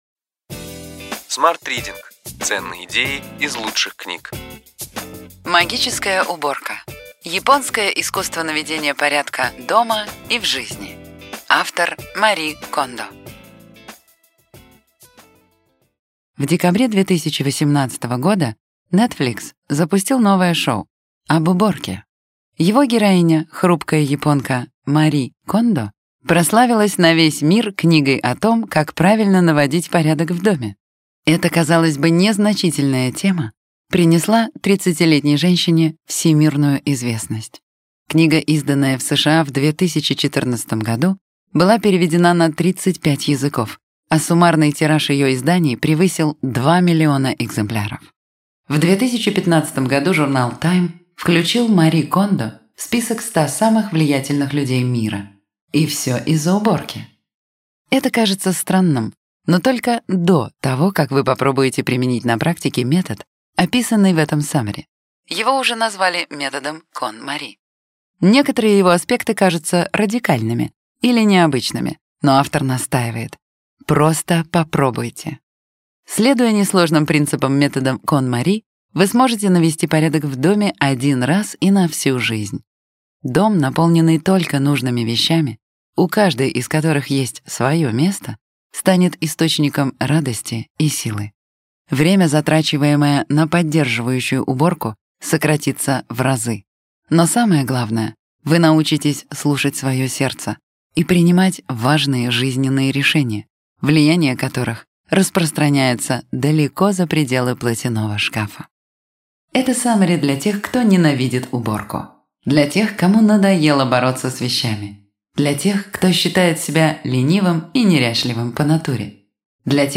Аудиокнига Ключевые идеи книги: Магическая уборка. Японское искусство наведения порядка дома и в жизни.